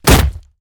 fixed kick sounds
flesh2.ogg